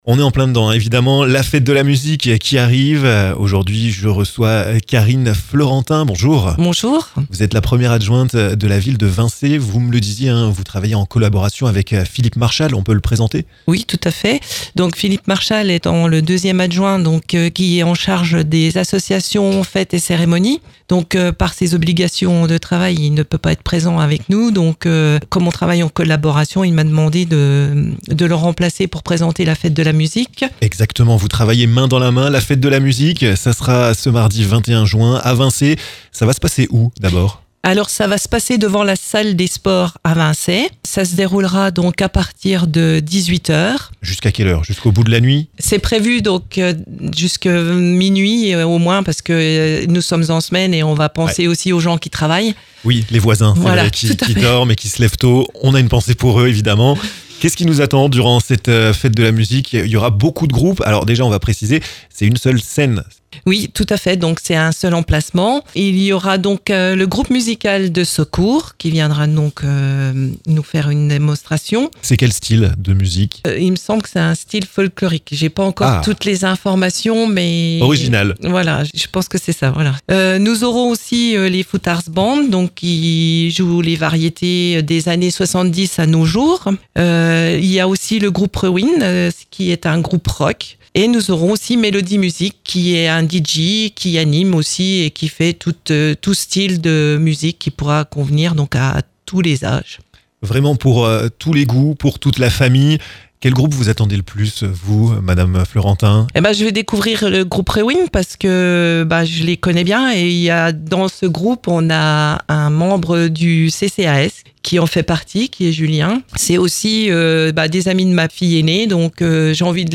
Karine Fleurentin, 1ère adjointe, vous en dit plus sur la programmation de cette soirée!